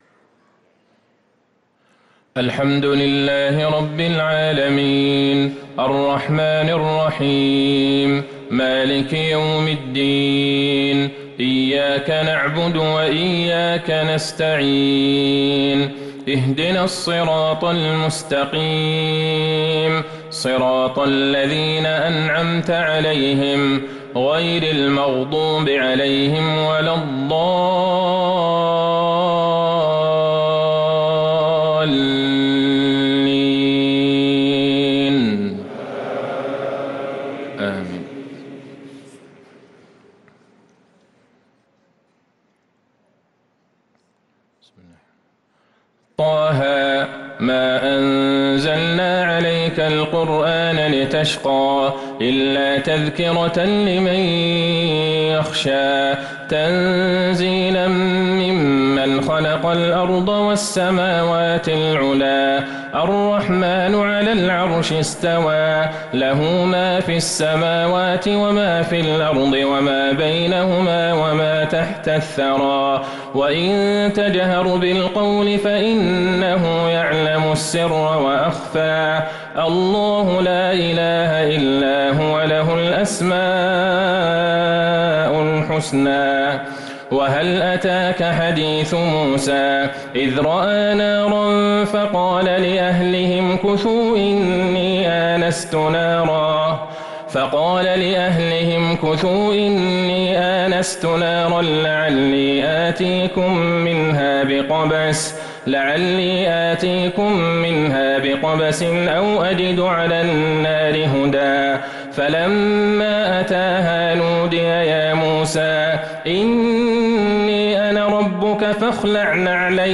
صلاة العشاء للقارئ عبدالله البعيجان 20 جمادي الأول 1445 هـ
تِلَاوَات الْحَرَمَيْن .